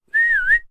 attention-whistle.wav